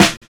Snare set 2 002.wav